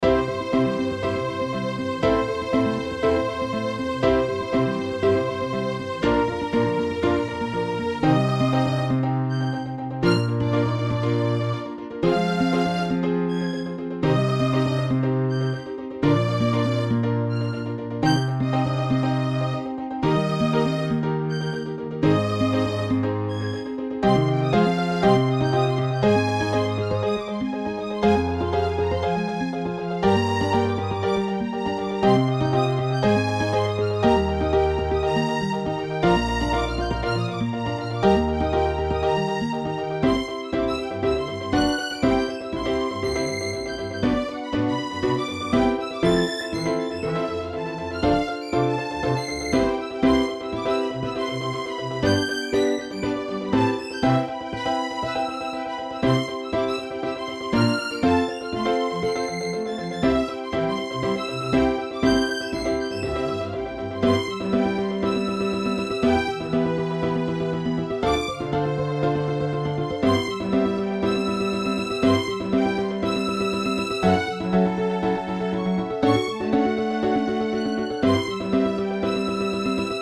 ストリング アンサンブル2、オーケストラル ハープ、コントラバス、ピアノ
種類BGM